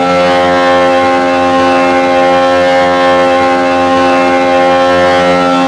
rr3-assets/files/.depot/audio/Vehicles/v6_f1/f1_v6_high.wav
f1_v6_high.wav